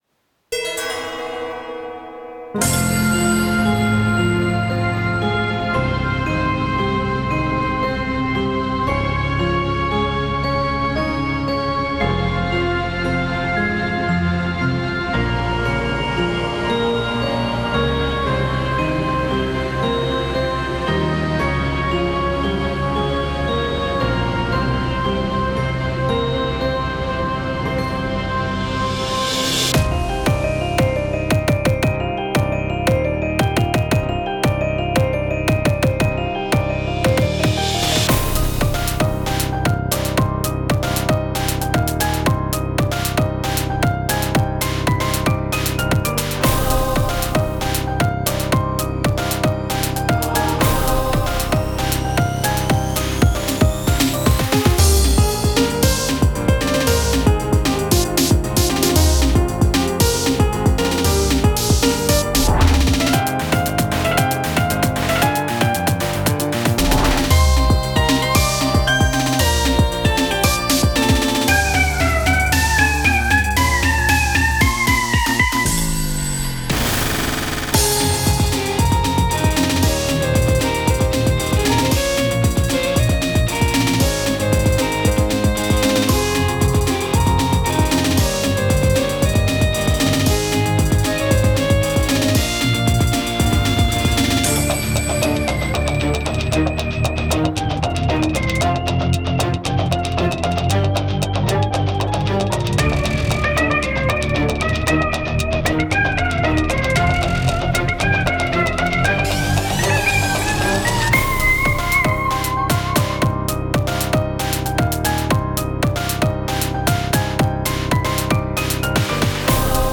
Battle4srLOOP OGG